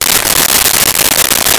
Wrapper Opened 03
Wrapper Opened 03.wav